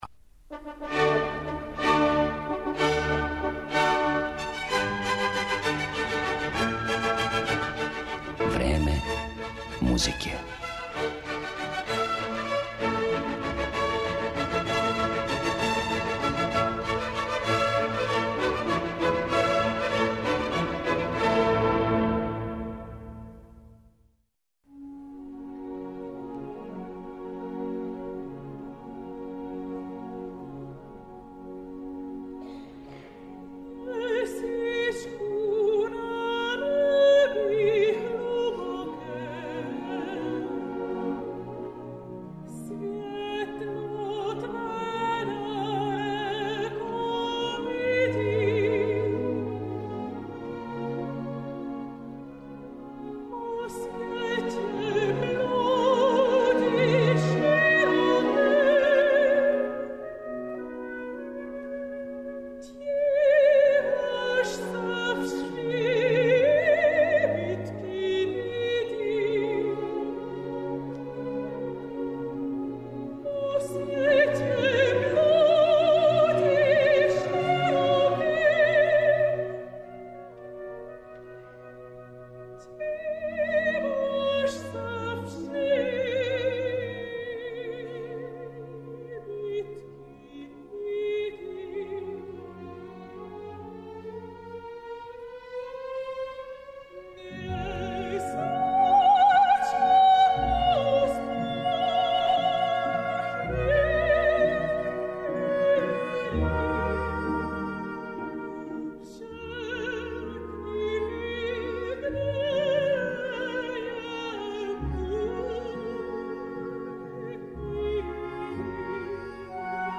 Фински сопран Карита Матила убраја се међу најпознатије и најцењеније вокалне уметнике у свету.
Управо тај сегмент њеног ангажмана представља музичку окосницу данашње емисије - бићете у прилици да чујете избор песама Брамса, Дебисија, Сибелијуса и Берга.